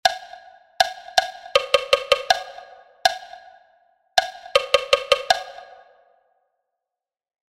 motivo_ritmico_1.mp3